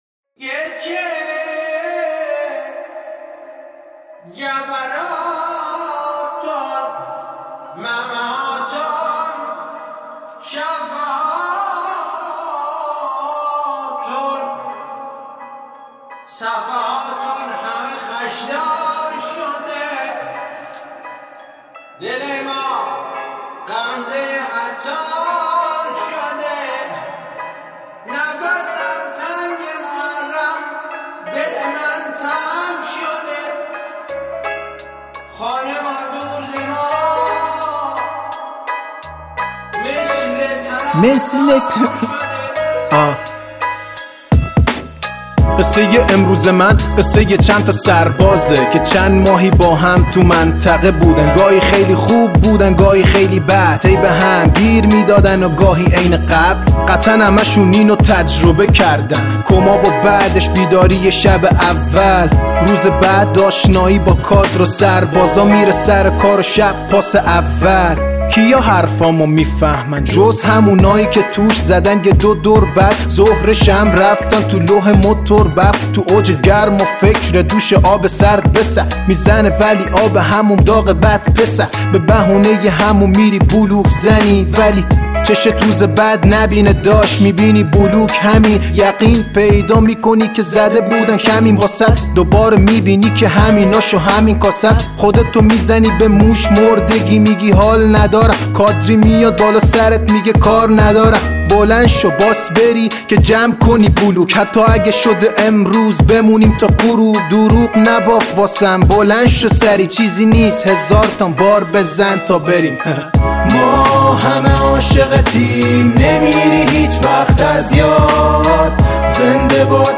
Hiphop
هیپ هاپ